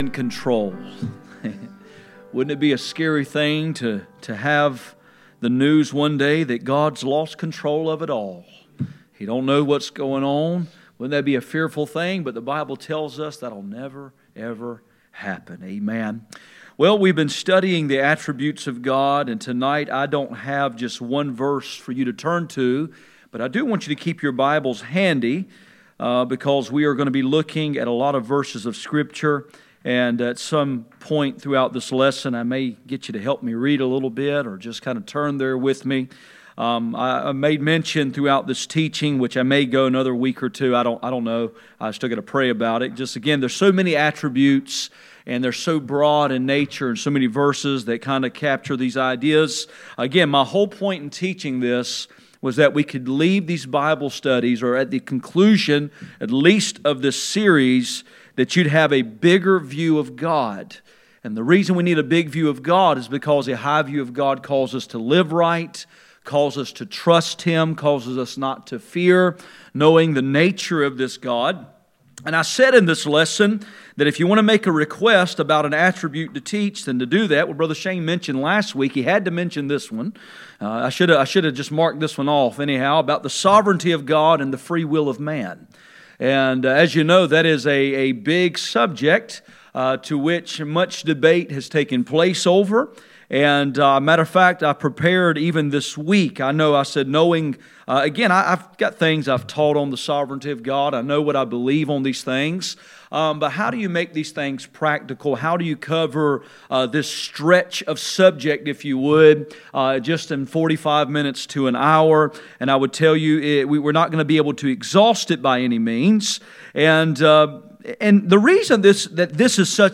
None Service Type: Midweek Meeting %todo_render% « Row